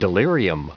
Prononciation du mot delirium en anglais (fichier audio)
Prononciation du mot : delirium